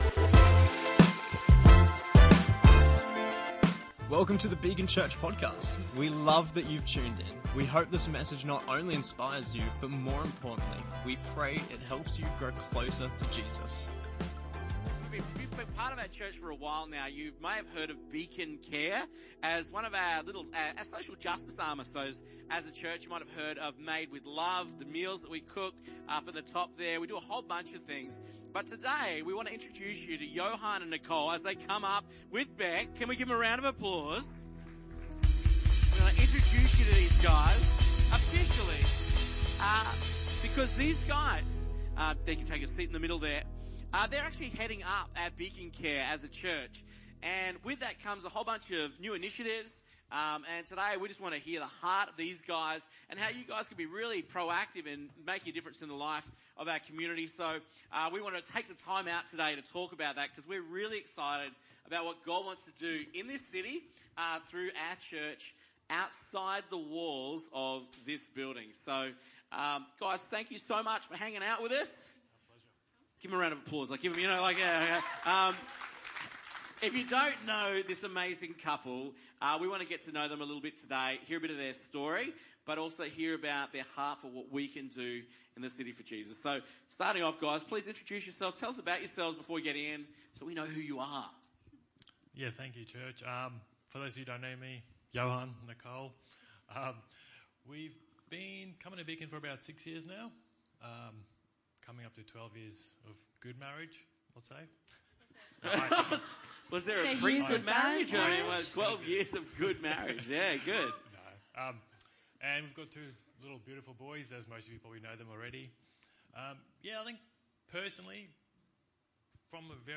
Episode 64: Beacon Care - Interview